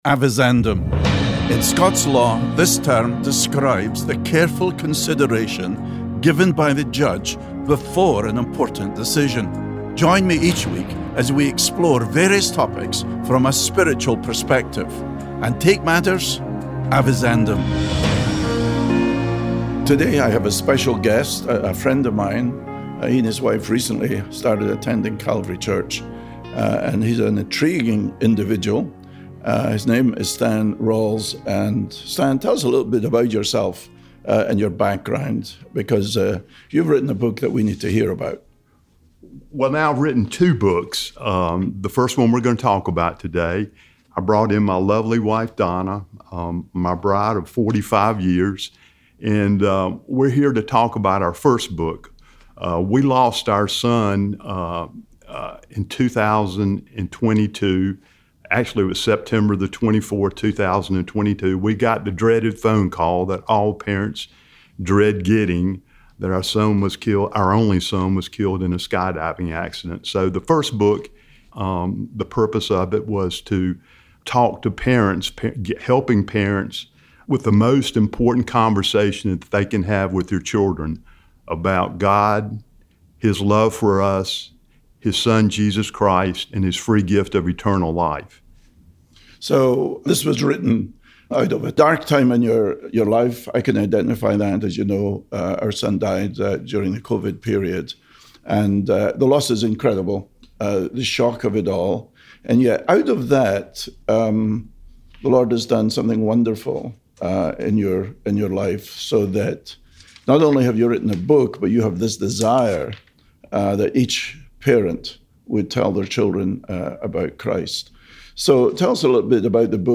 The Truth Network Radio